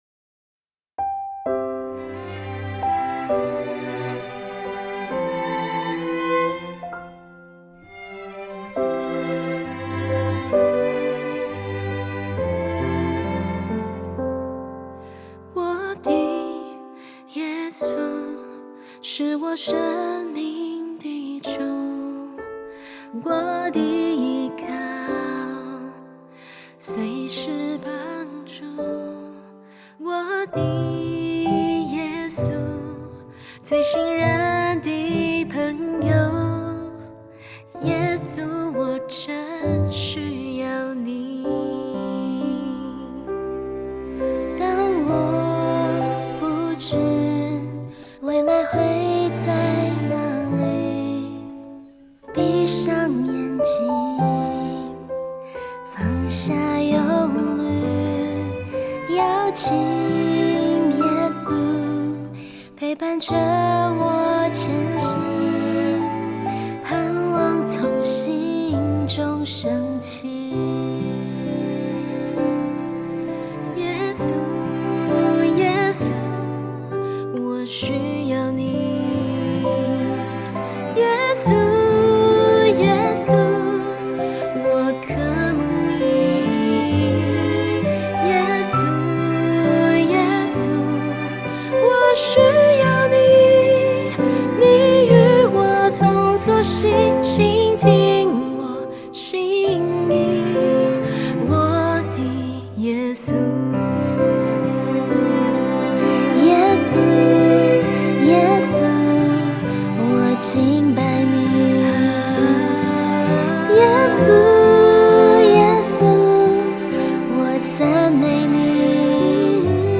以原声（Acoustic）的方式呈现